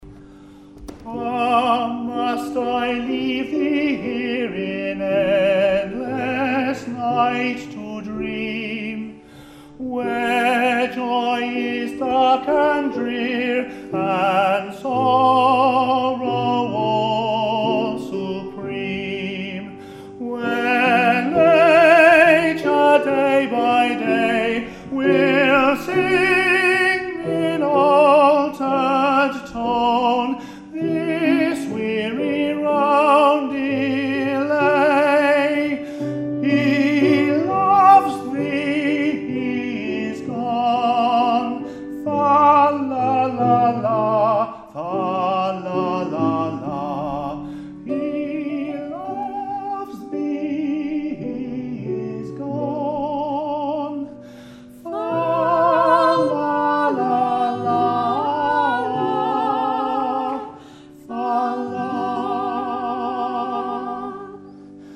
I sing 'Tenor' which is the higher and rarer of the male voice categories.
Couple of live samples of me singing 'Frederic' Pirates Of Penzance in a local community production in 2004.